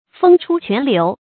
蜂出泉流 fēng chū quán liú
蜂出泉流发音